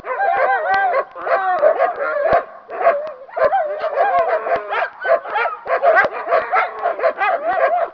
Pack of Dogs Download
Packofdogs.mp3